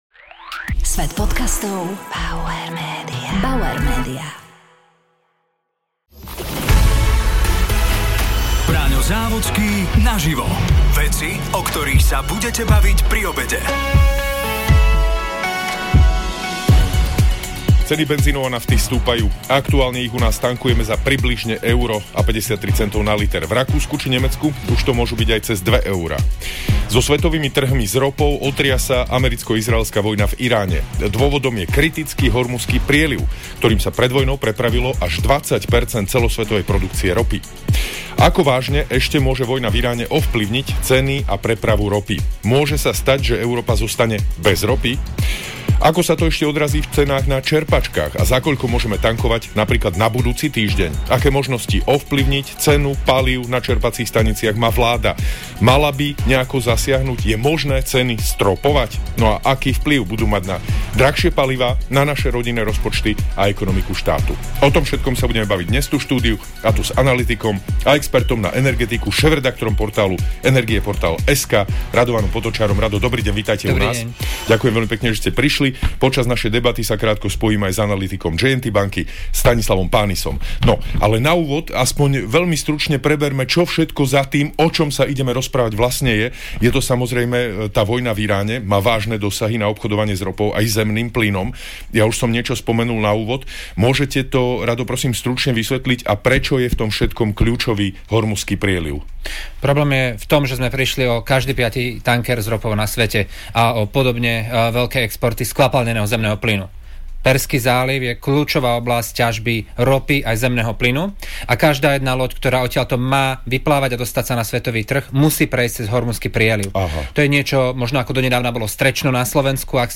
sa rozprával s analytikom a expertom na energetiku